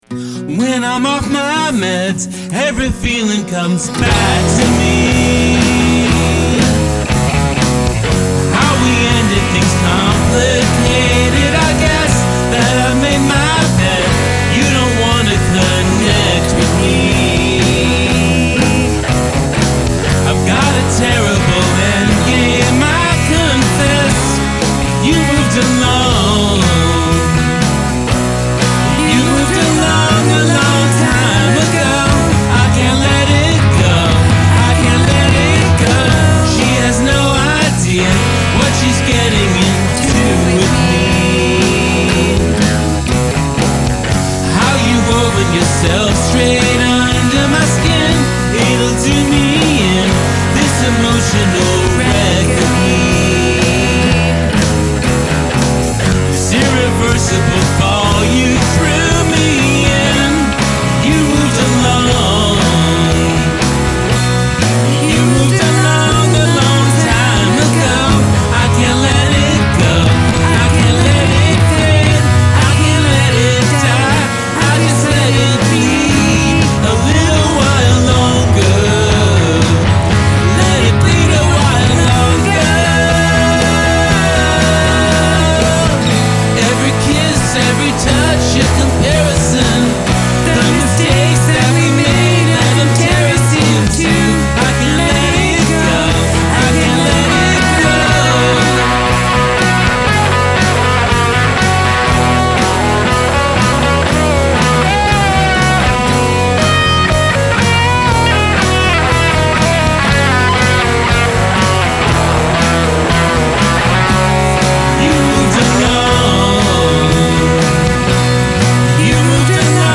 loving your work, this is lo-fi powerpop gold.